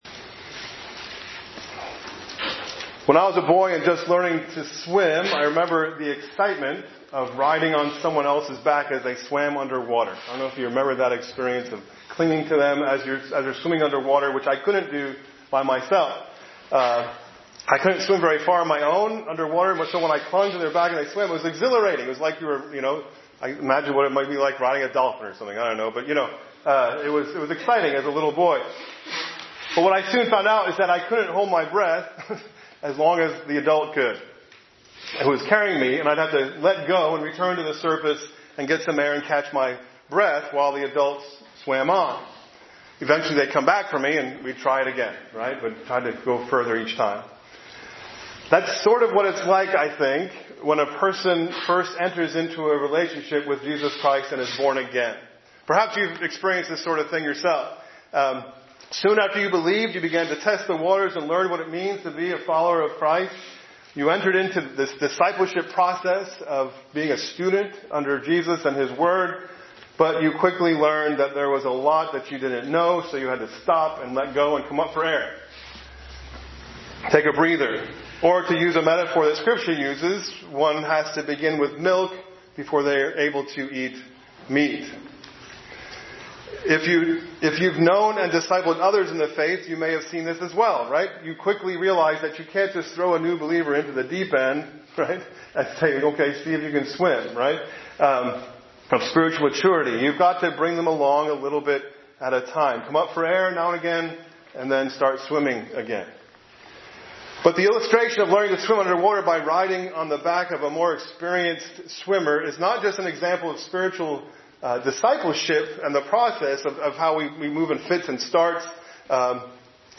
A message from the series "Easter."